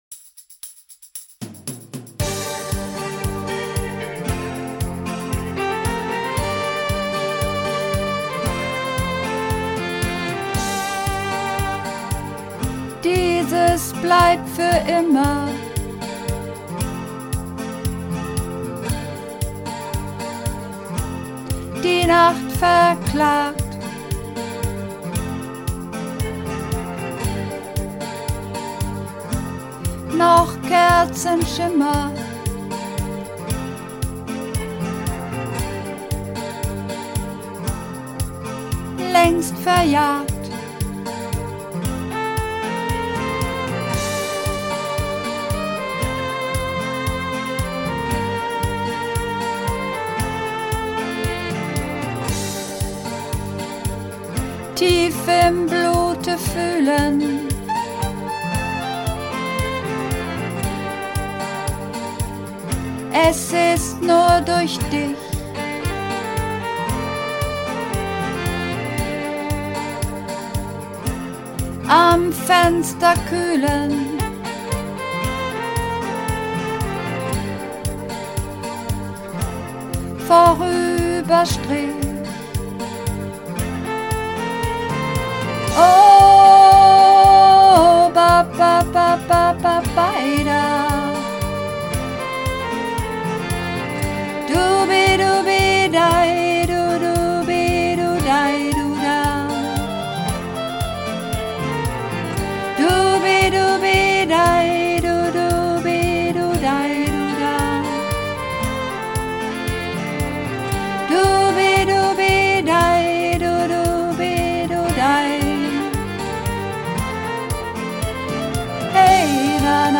Übungsaufnahmen - Am Fenster
Am Fenster (Sopran)
Am_Fenster__4_Sopran.mp3